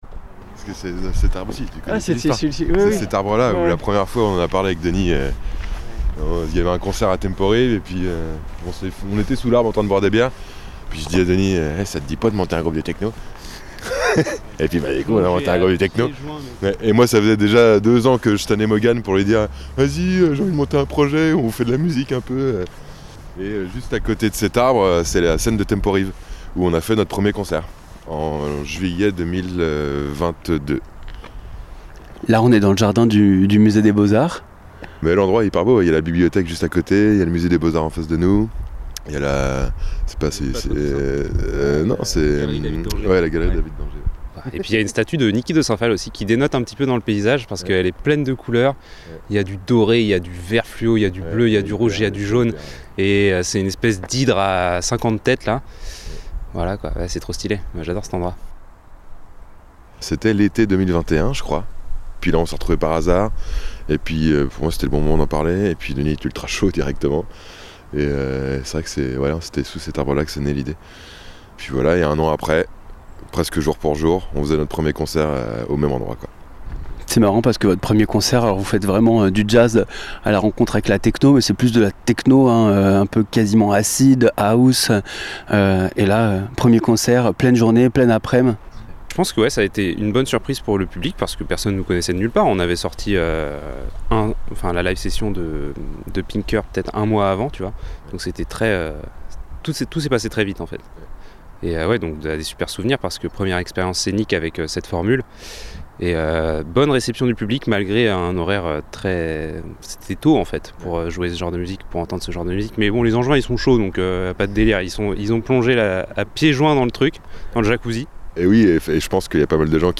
Reportage du groupe Hyper Jacuzzi – Inouïs du Printemps de Bourges 2024